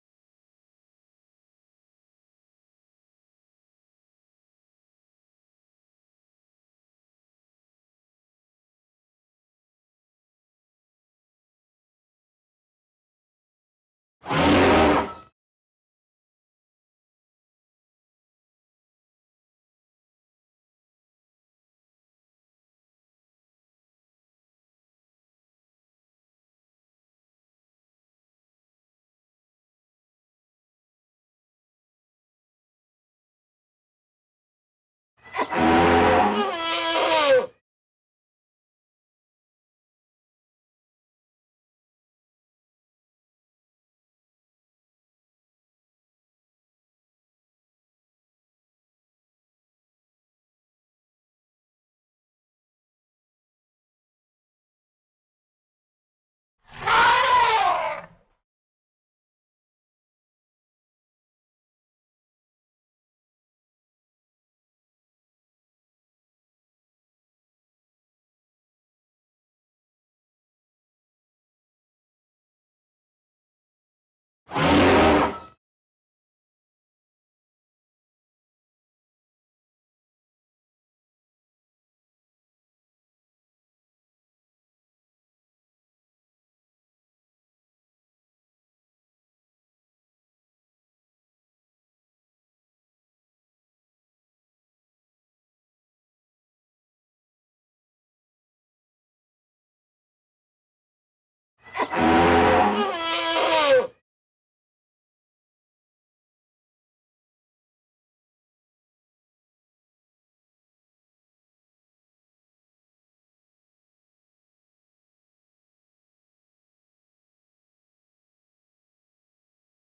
SFX_Intro_Elephant_02.ogg